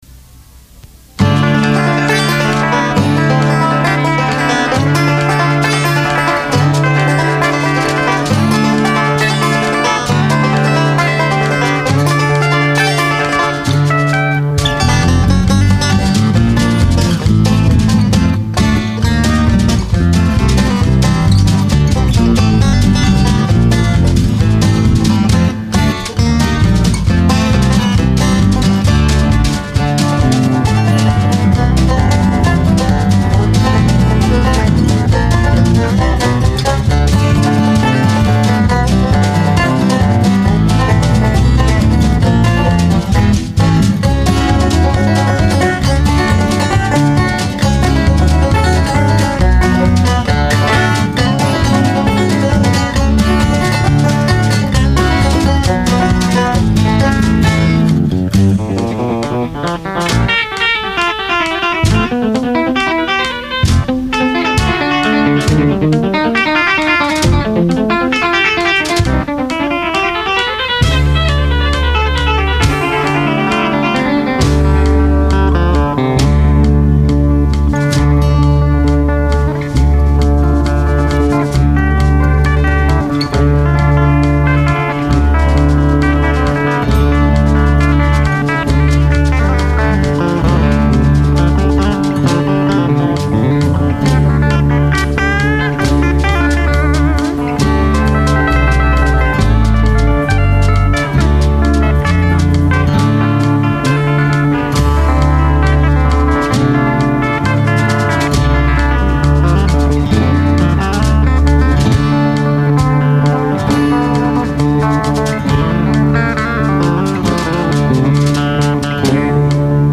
67 Telecaster guitar, bass, banjo
Drums